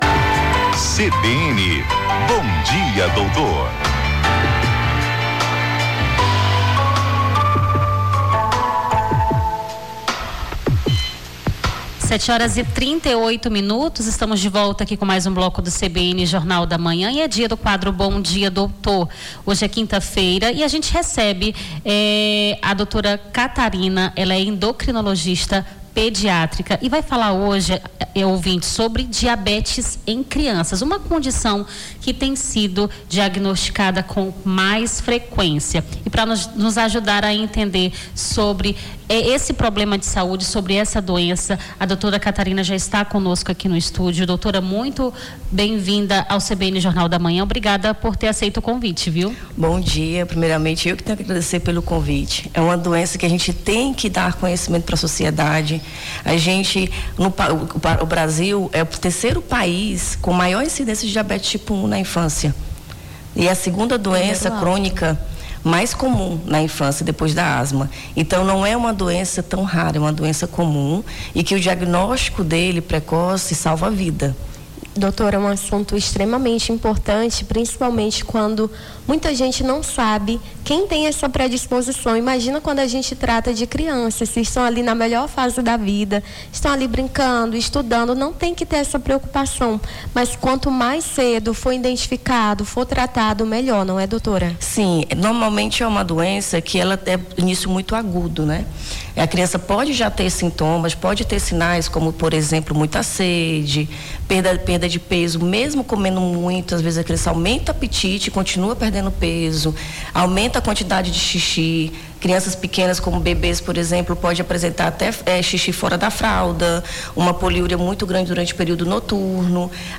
Bom dia, doutor: endocrinologista fala sobre diabetes na infância